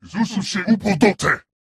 Sangheili voice clip from Halo: Reach.